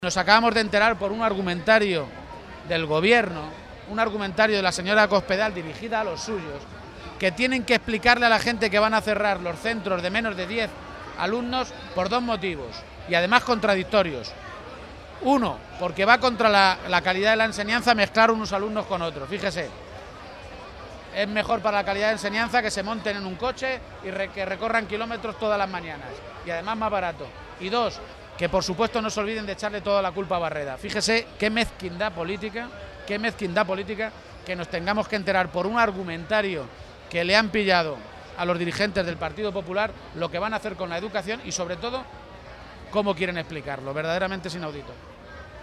Emiliano García-Page, Secretario General del PSCM-PSOE
Respondía así García-Page a preguntas de los medios de comunicación, durante su visita a la Feria de Talavera de la Reina, sobre la posibilidad de acordar las cuentas regionales de este año, que aún no están presentadas a pesar de estar ya a mediados del mes de mayo.